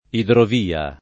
[ idrov & a ]